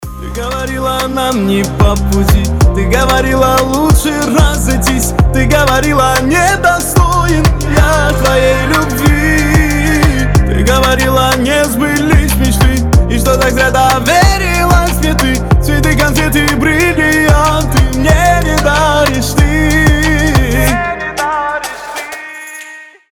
грустные
кавказские